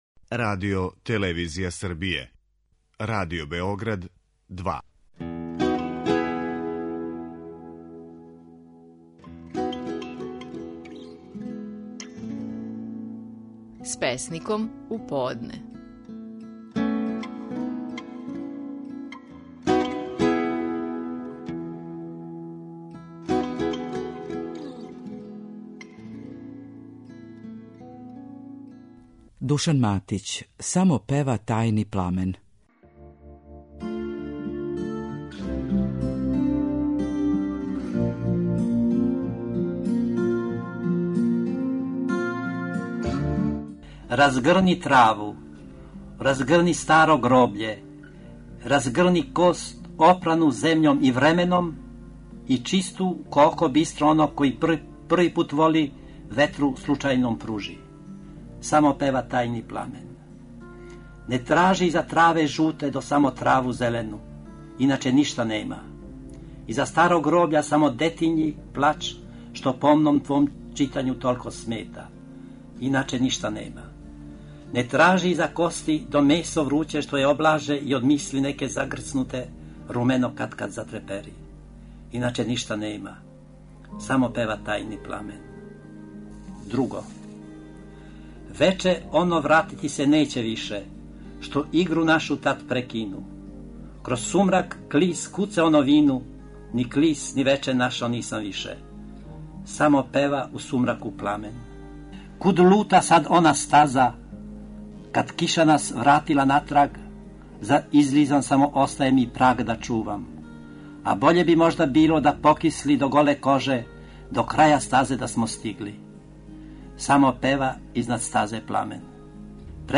Стихови наших најпознатијих песника, у интерпретацији аутора
Душан Матић говори своју песму "Само пева тајни пламен".